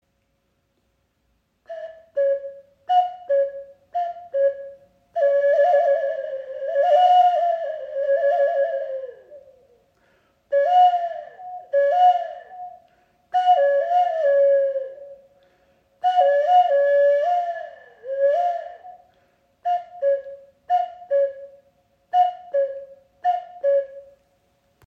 • Icon Täuschend echter Kuckucksruf – Loch einfach auf- und abdecken.
Loch auf- und zuhalten, stossweise blasen – schon erklingt der Kuckucksruf.
Kuckuck-Flöte | Gross